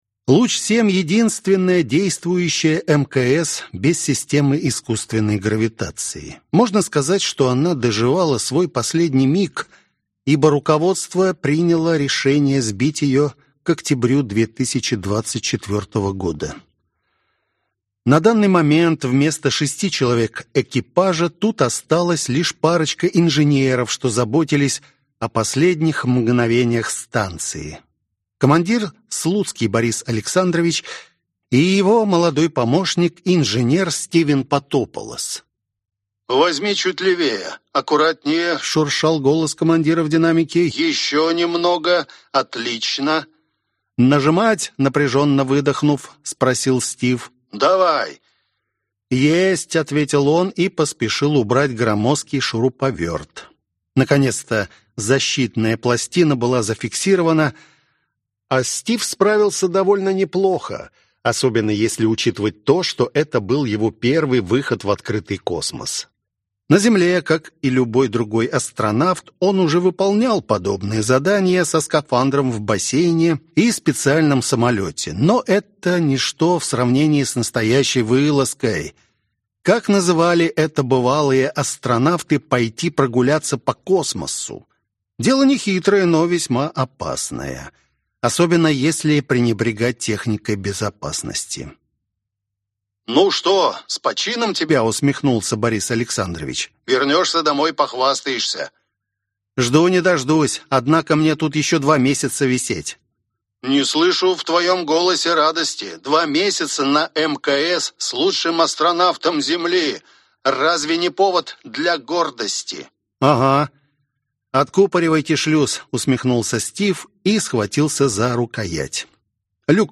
Аудиокнига Горизонт событий. Книга 6. Эргосфера. Том 1 | Библиотека аудиокниг